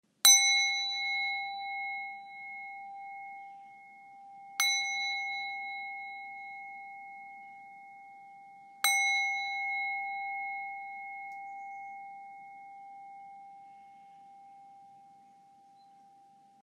Cuenco Tibetano de Viaje Rojo 7,5 cm
Sus gruesas paredes producen sonidos de extraordinaria duración. También vibra cuando se desliza el mazo en torno al borde superior externo variando la presión o la velocidad.
• Descripción: Ø 7,5 cm x H 4,5 cm. Aleación de cobre y zinc. Con mazo, cojín brocado y funda artesanal.